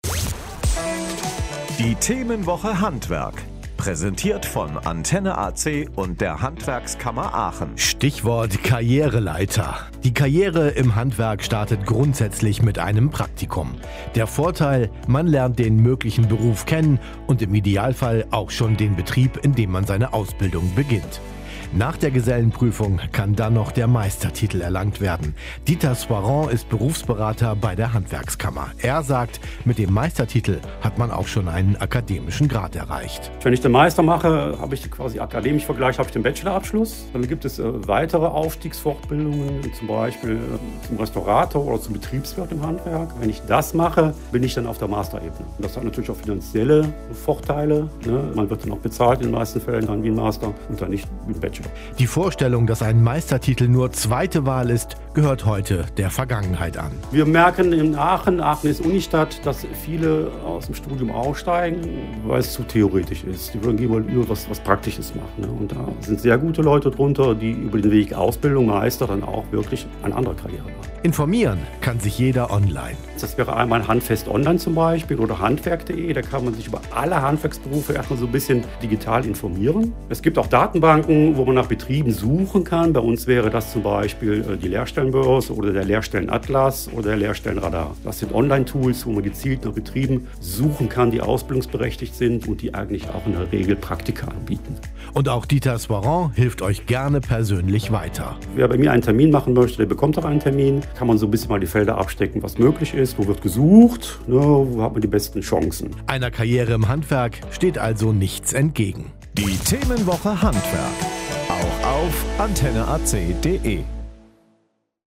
Radiobeiträge: Karriereleiter im Handwerk